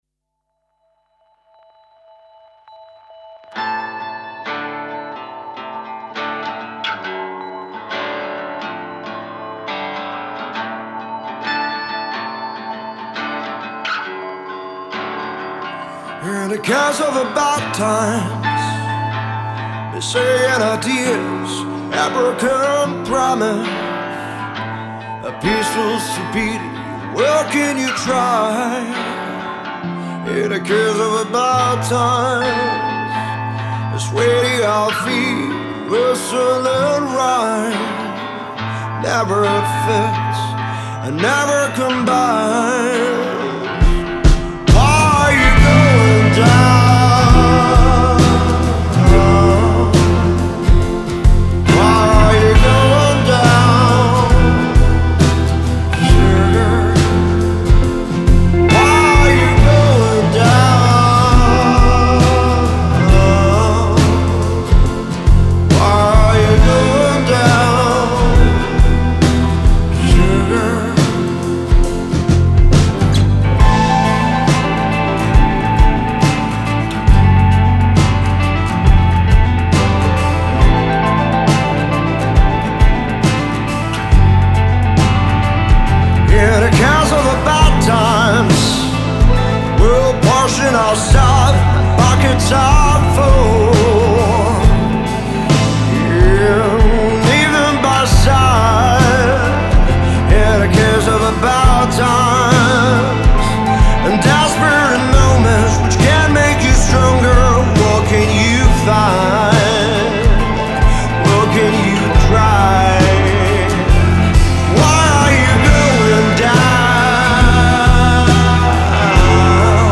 Ich habe jetzt mal auf die schnelle Sculpt vor Pro L gesetzt. Wahrscheinlich tanzen hier sie Seiten etwas zu weit, aber egal.